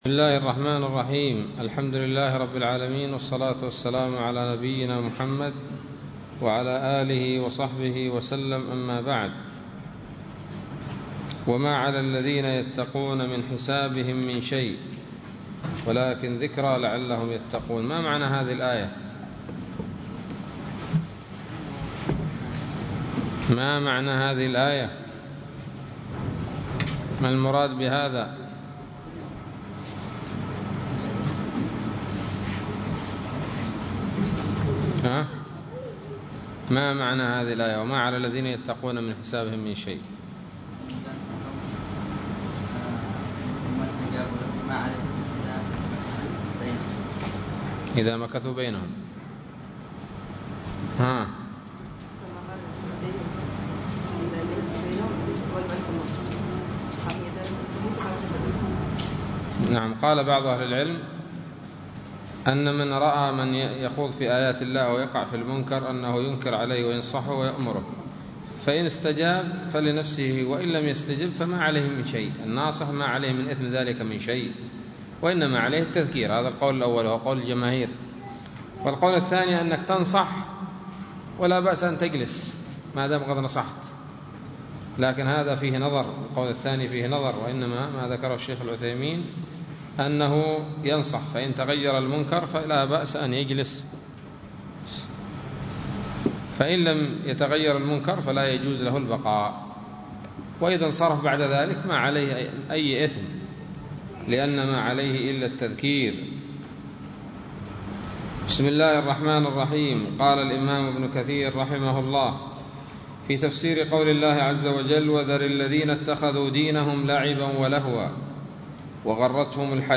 006 سورة الأنعام الدروس العلمية تفسير ابن كثير دروس التفسير